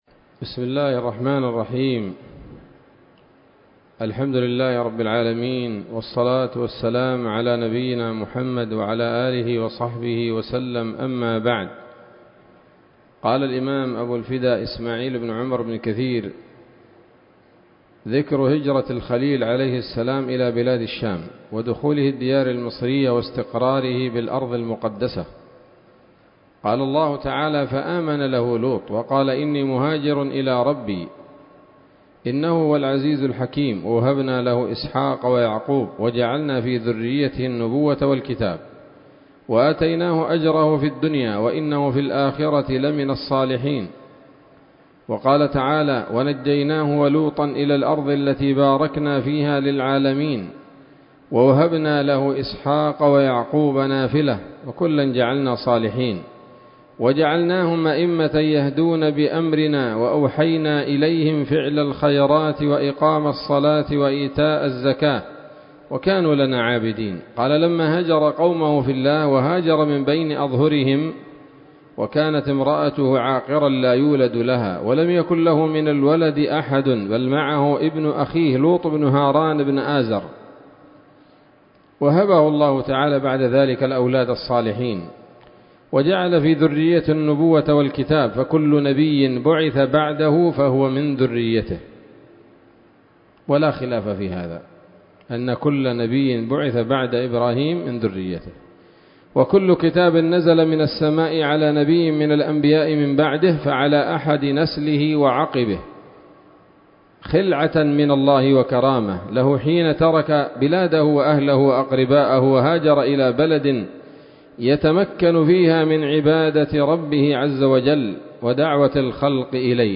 الدرس الخامس والأربعون من قصص الأنبياء لابن كثير رحمه الله تعالى